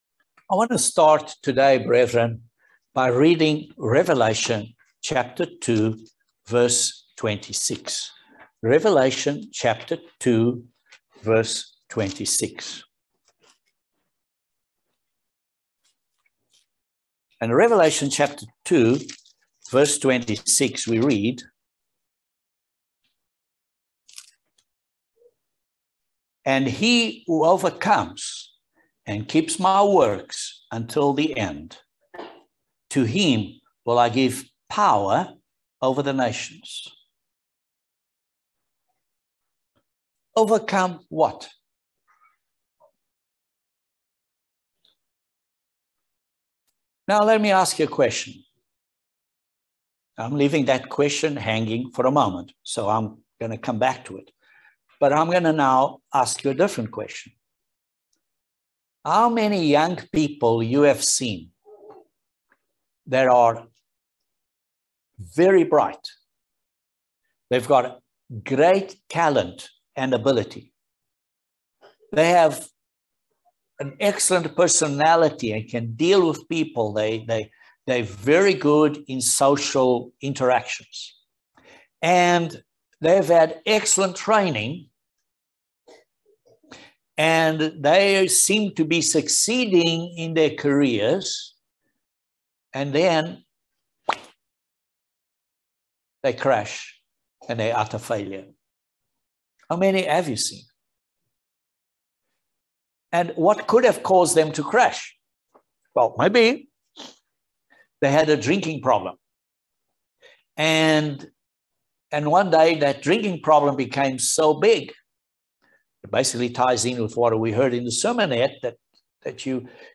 Join us for this very important video sermon on 3 keys to develop Godly Character. We must learn to master ourselves and develop Godly Character.